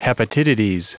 Prononciation du mot hepatitides en anglais (fichier audio)
Prononciation du mot : hepatitides